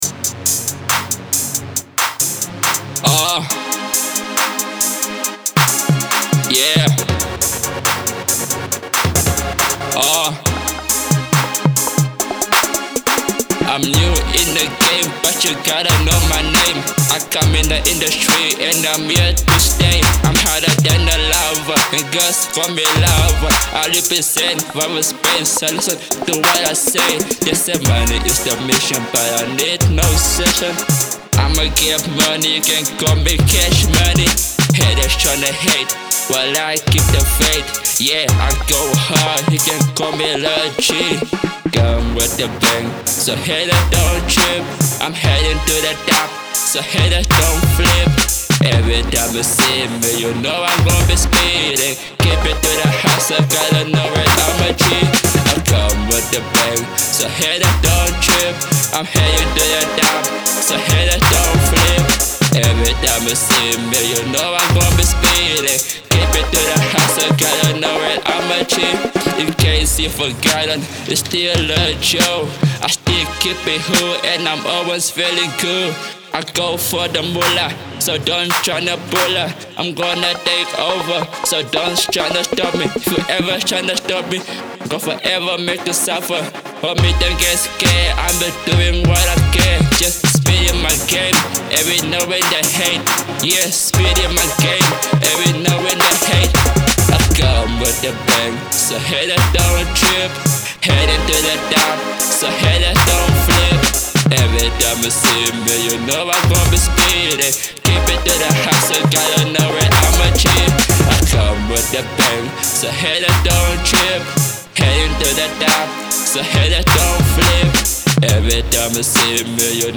With its energetic rhythm and modern hip-hop sound